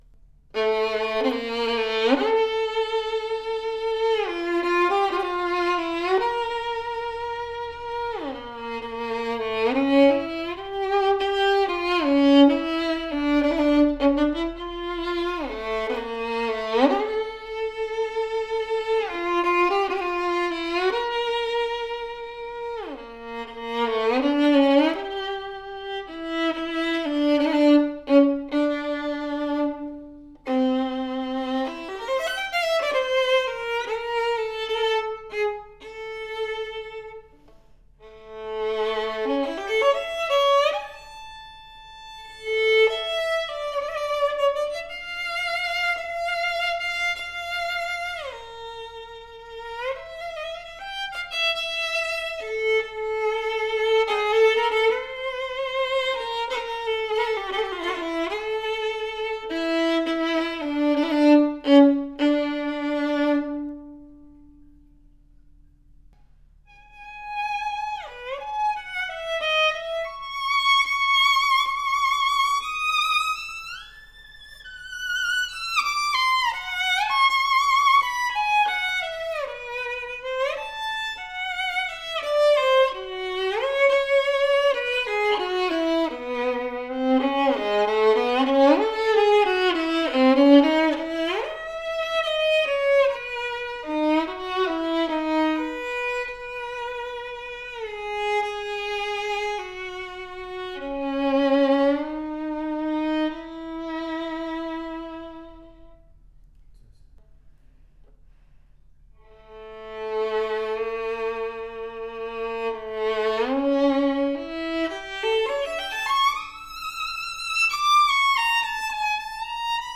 Audio clip ( Repertoire ):
POWERFULL, dark, projective tone with fantastic projection that carries the tone on distance!
Deep ringing G string, great depth and sings with bold dimension. Sweet and focused mid register that speaks with clarity, clean E string with a singing tone quality.